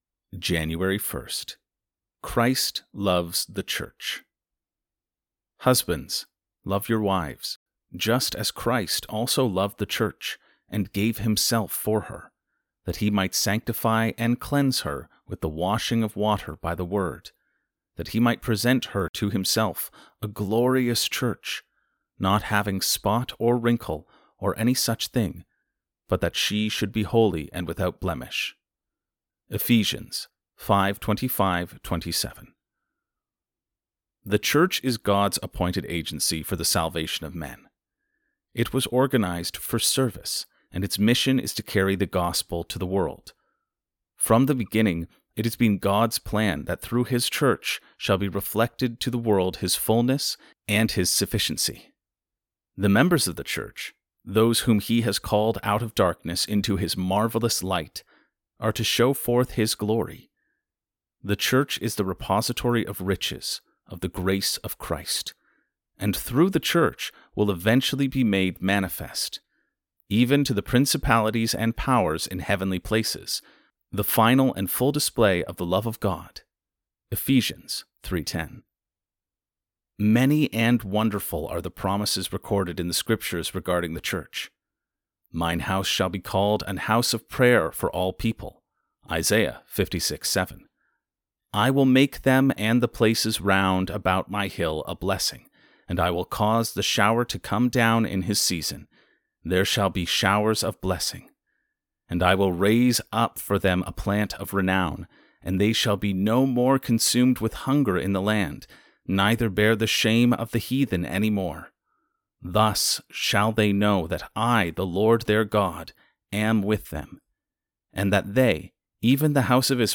Audiobook Versions Built-In Listen on-the-go or add a new layer to your devotional time with our narrated versions. Listen to Sample Audio Daily Prayer Journal Capture your thoughts, reflections, and prayers each day, creating a meaningful journal of your journey.